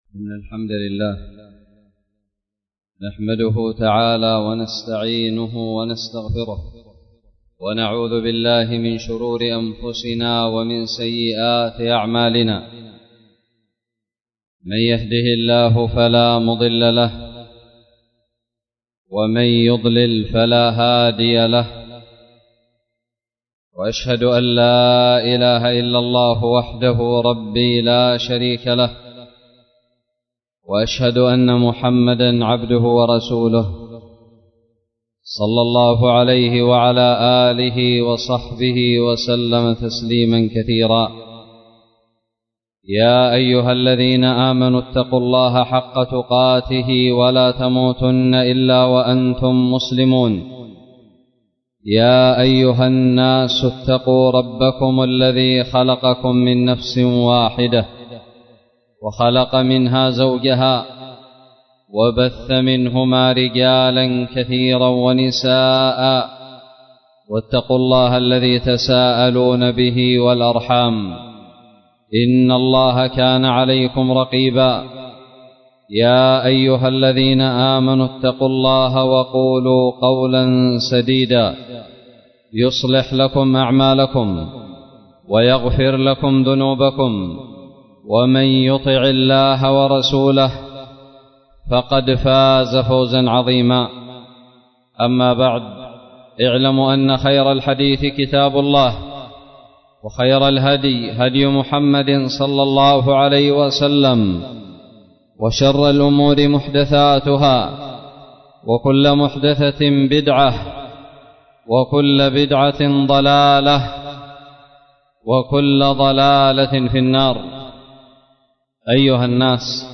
خطب الجمعة
ألقيت في دار الحديث السلفية بالمدي الصبيحة